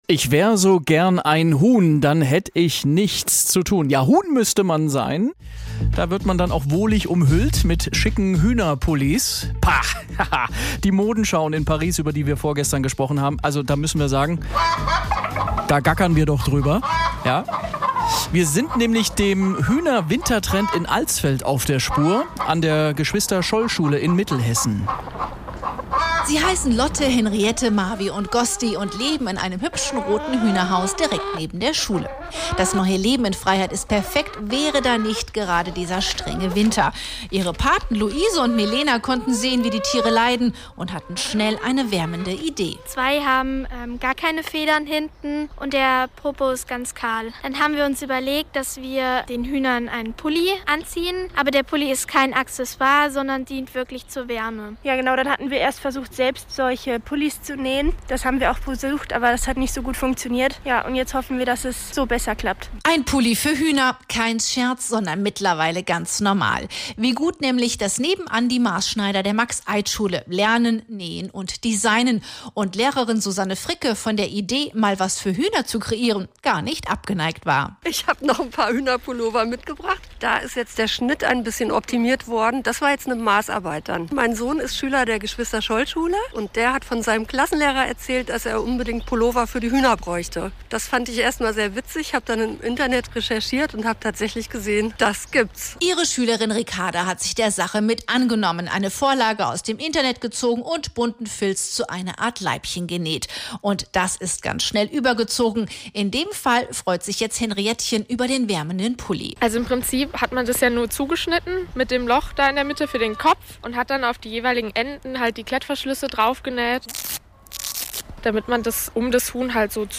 Hier der Audiobericht: